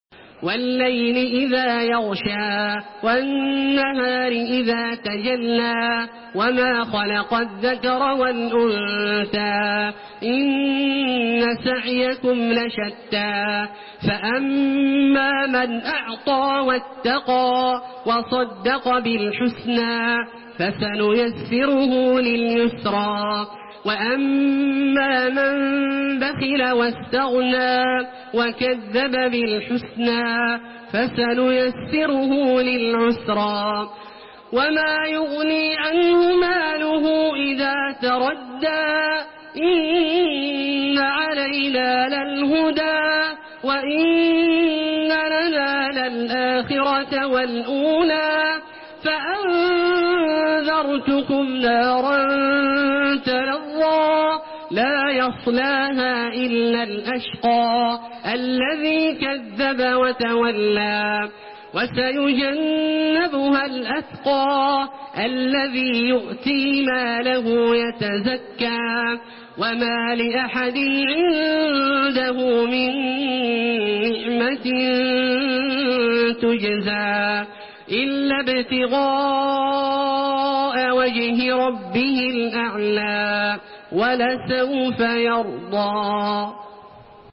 Surah الليل MP3 by تراويح الحرم المكي 1432 in حفص عن عاصم narration.
مرتل حفص عن عاصم